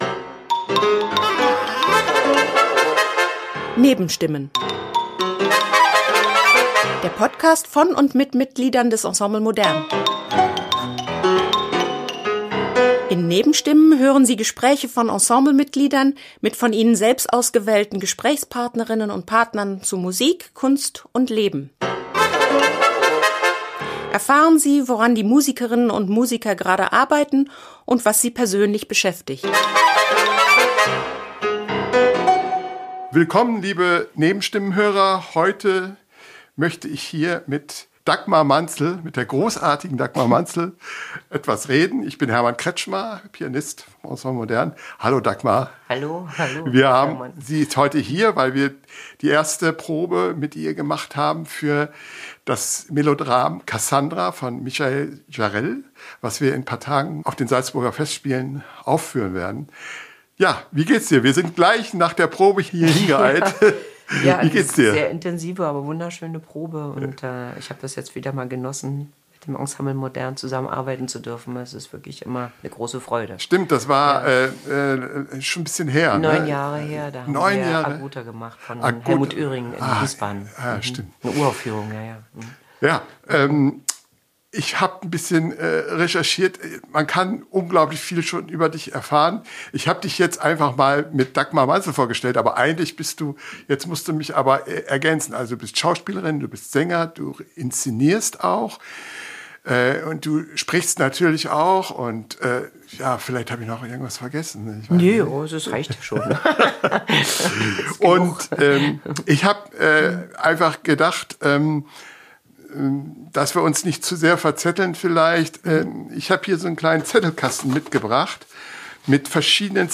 Beschreibung vor 8 Monaten In "Nebenstimmen" hören Sie Gespräche von Ensemble-Mitgliedern mit von ihnen selbst ausgewählten Gesprächspartnerinnen und -partnern zu Musik, Kunst und Leben.